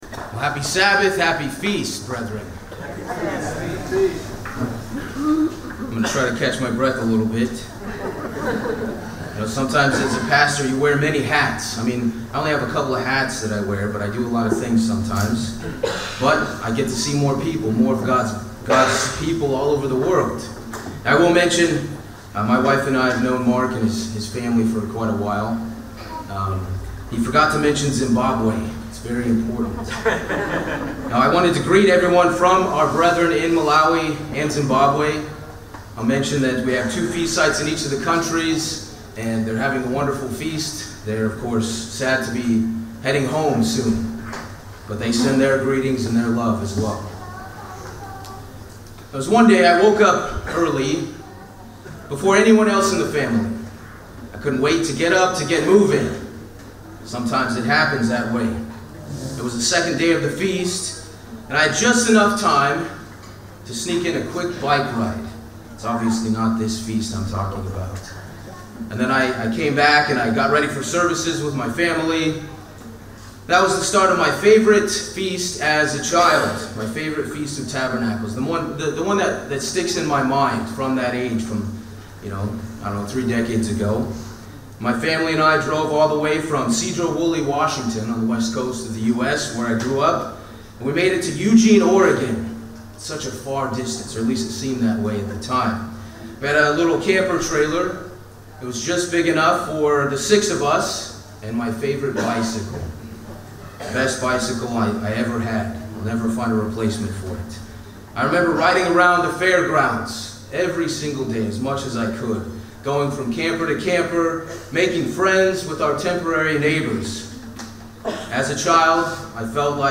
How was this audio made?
LGD 2024 Marina di Grosseto (Italy): Morning Service